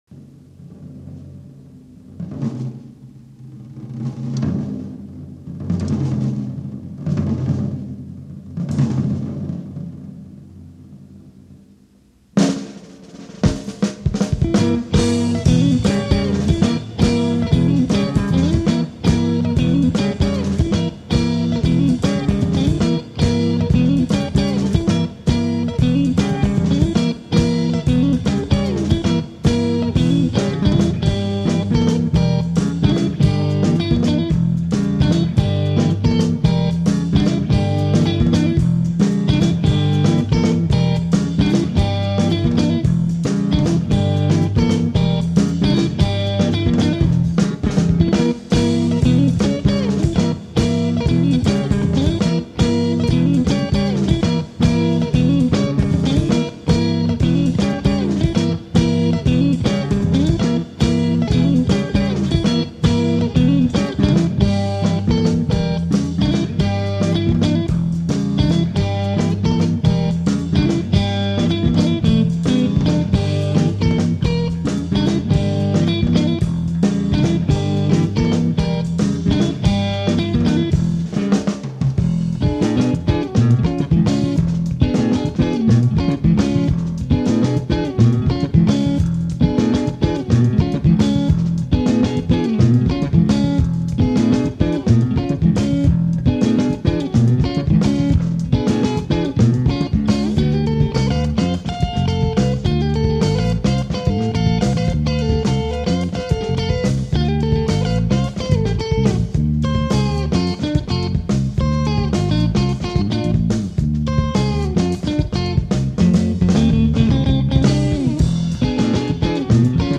gtr/vox
bass/vox
dr/vox